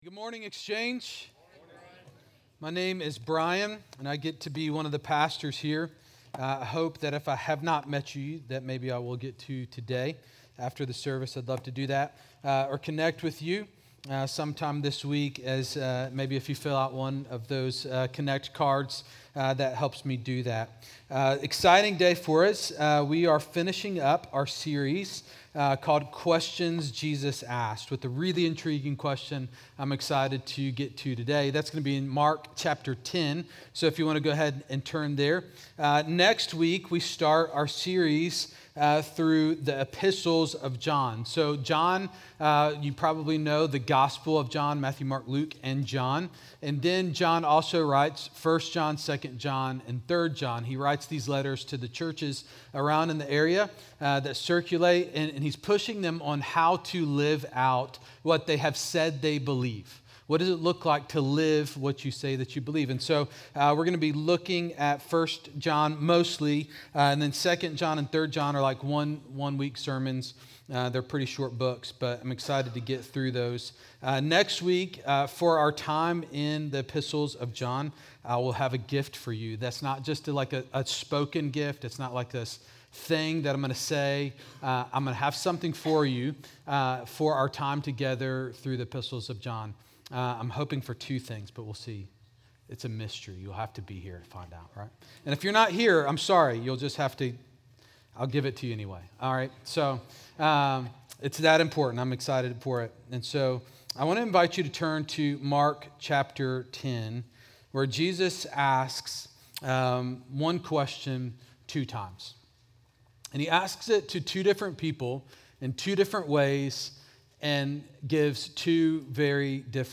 Exchange Church Sermons What Do You Want Me To Do For You?